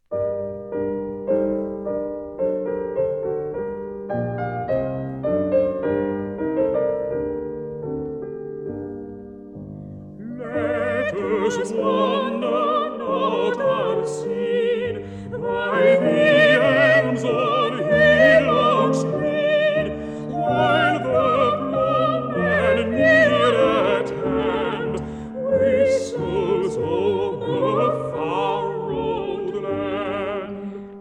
duets
soprano
baritone
piano